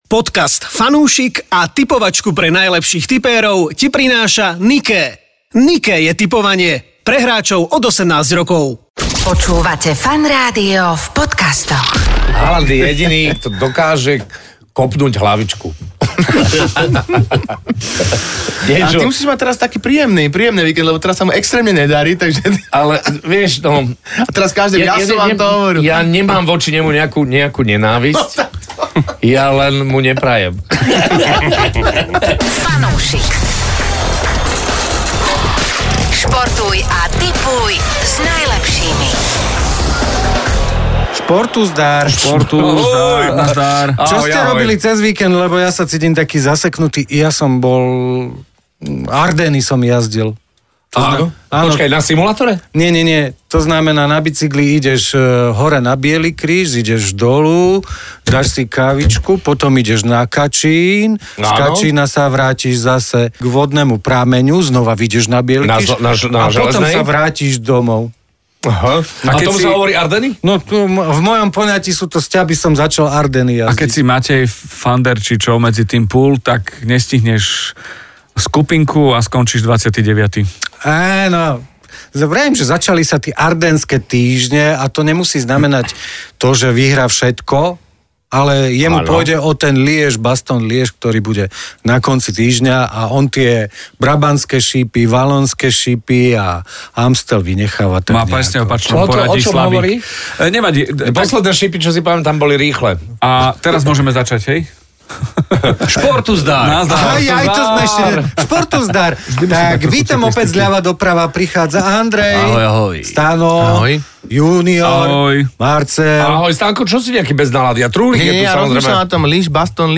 Daj si fanúšikovskú debatku o športe a tipovaní.